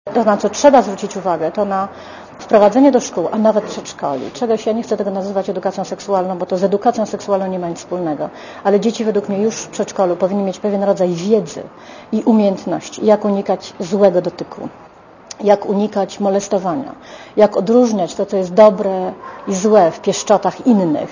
Posłuchaj komentarza Magdaleny Środy